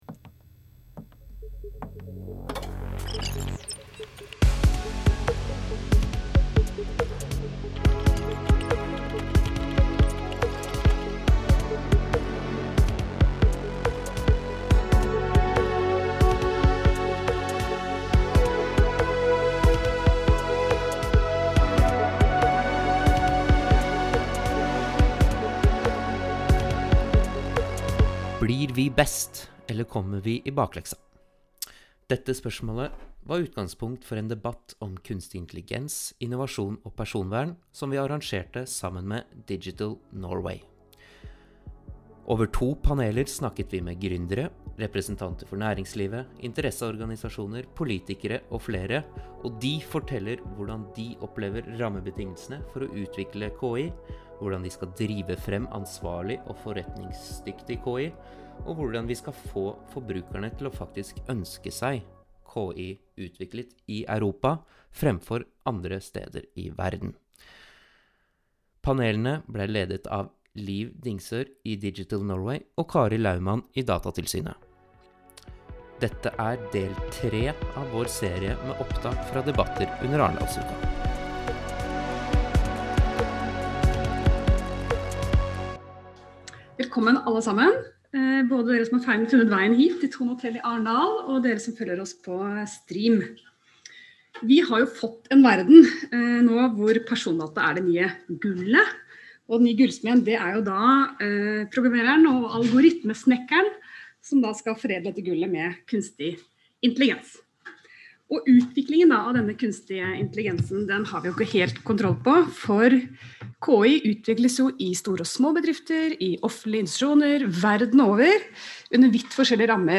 Dette er et opptak fra et av våre arrangementer under Arendalsuka 2021.
Datatilsynet og DigitalNorway inviterte til diskusjon som gikk over to paneler.